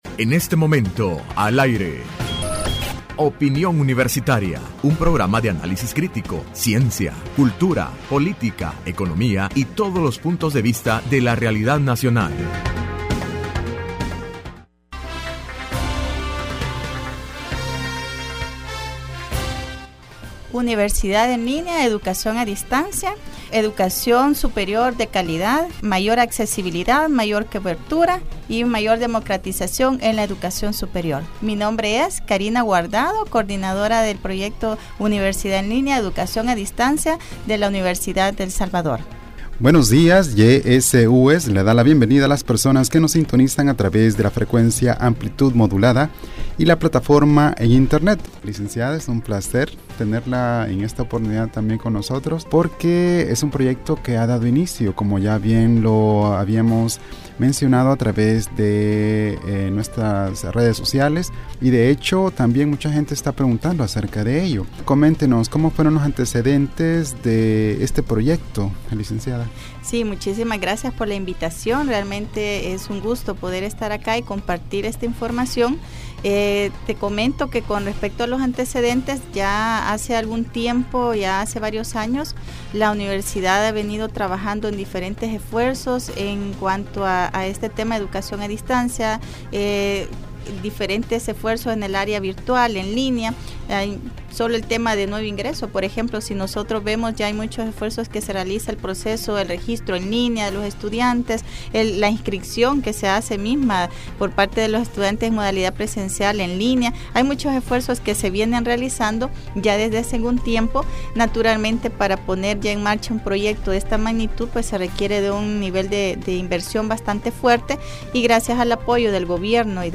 Entrevista Opinión Universitaria(27 de Enero 2016): Universidad en linea/Educación a distancia UES